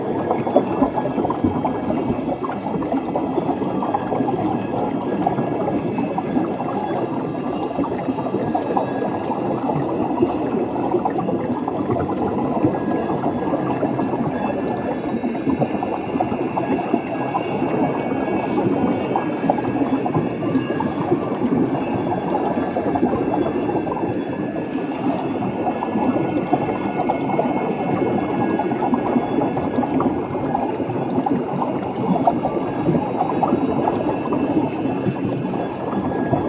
Sfx_creature_jellyfish_presence_01.ogg